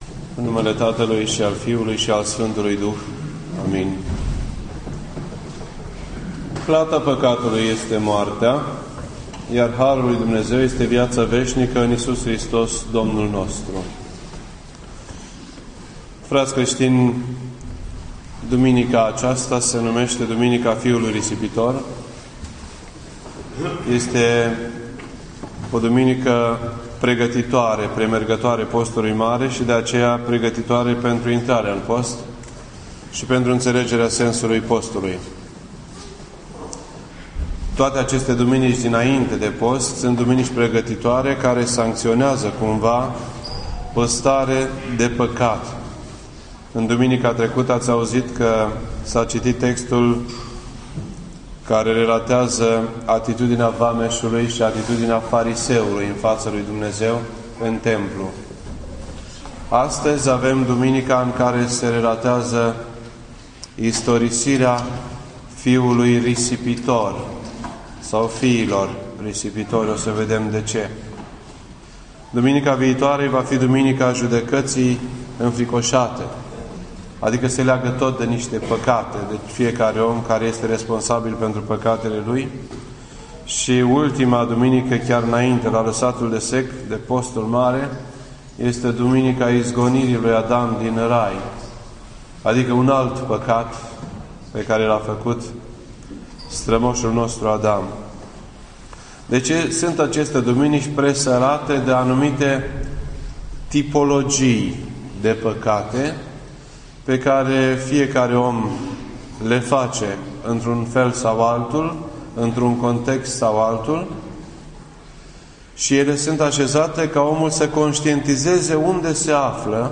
This entry was posted on Sunday, January 29th, 2012 at 8:37 PM and is filed under Predici ortodoxe in format audio.